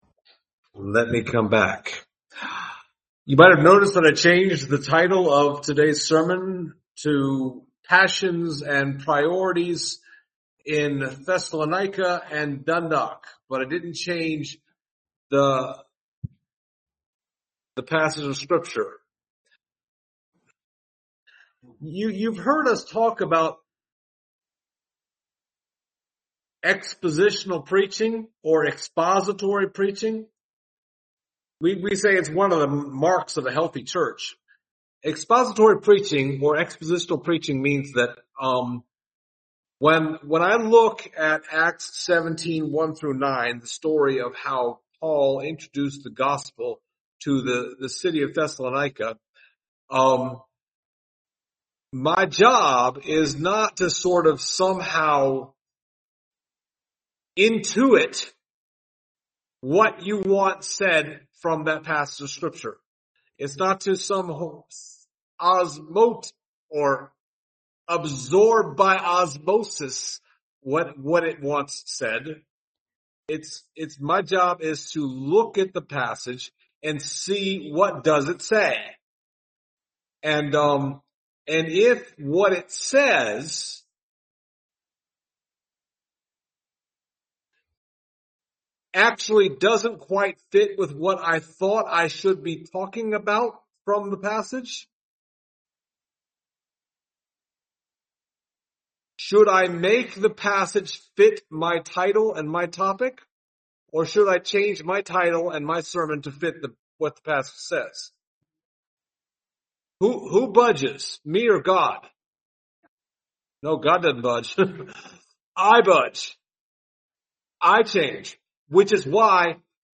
Passage: Acts 17:1-9 Service Type: Sunday Morning